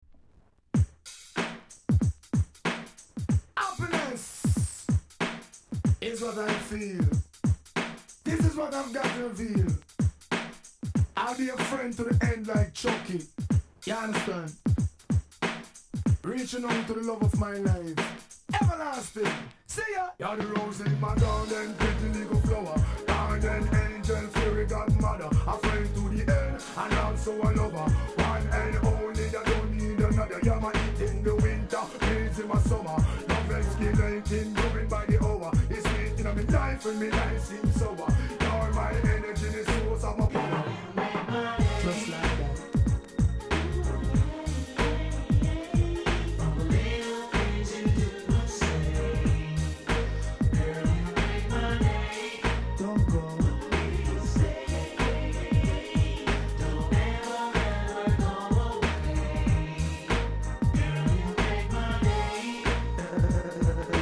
RAGA HIP HOP